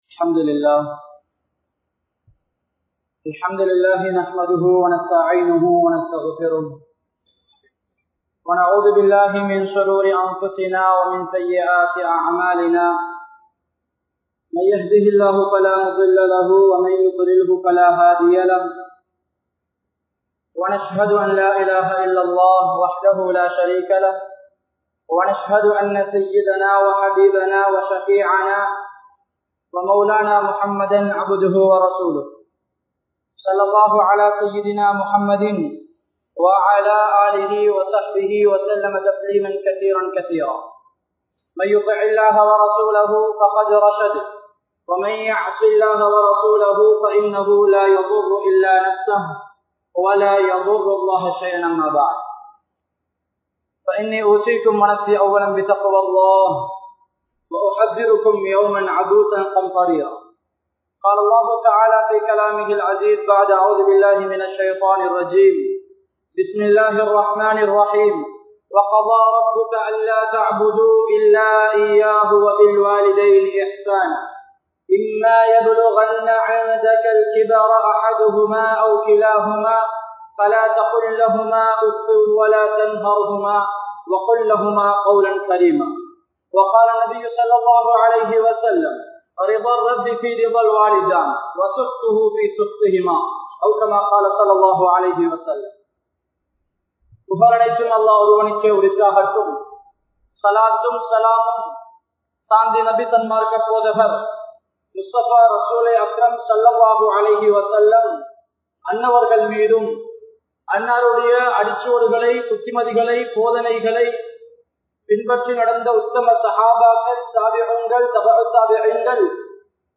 Un Suvanam Un Petroarhalthaan (உன் சுவனம் உன் பெற்றோர்கள்தான்) | Audio Bayans | All Ceylon Muslim Youth Community | Addalaichenai
Gongawela Jumua Masjidh